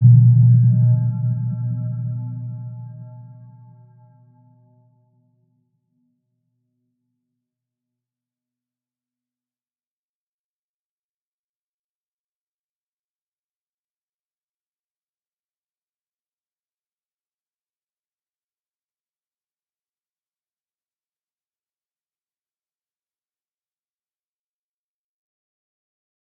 Round-Bell-B2-f.wav